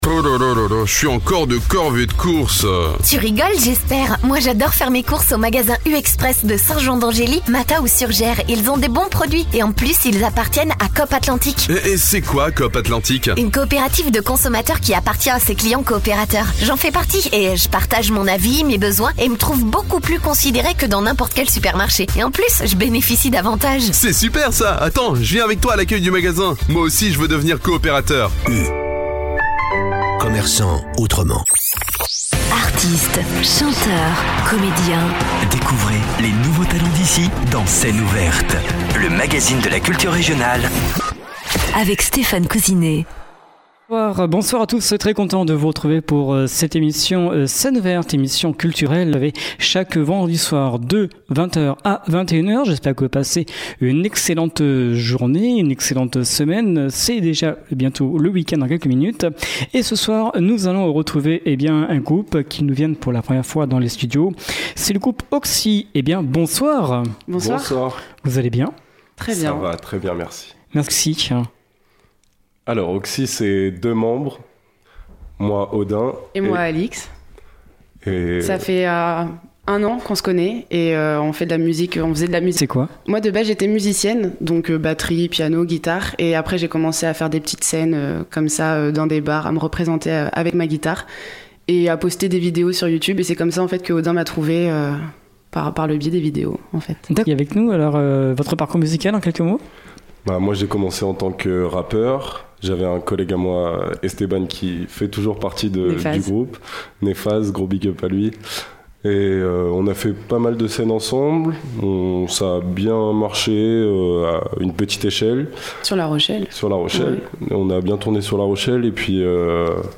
chanteuse influencée par la soul
pour une dose de pop peu commune…